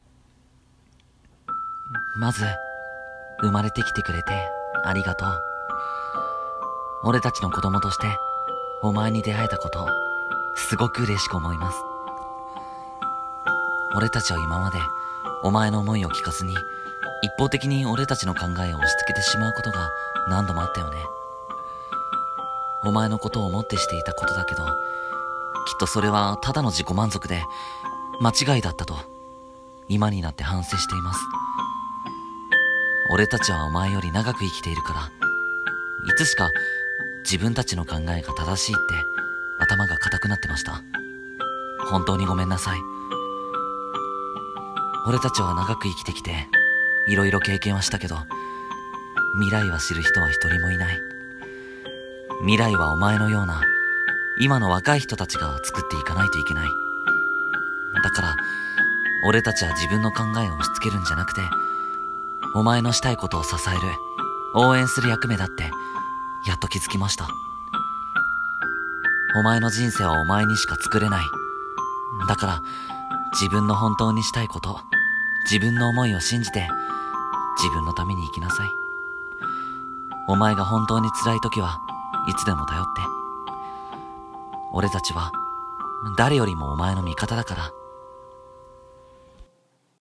【一人声劇】親からの手紙。